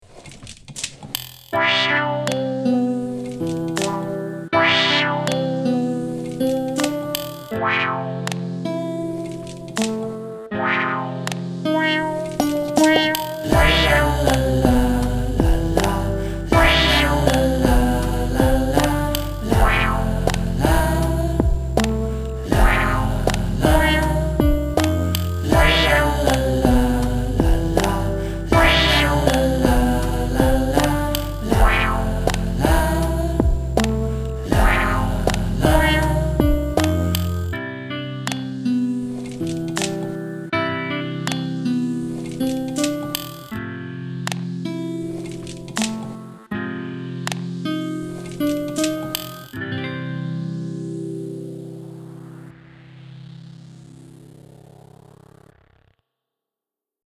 Warm twisted lullaby with 'lalalalala' vocal.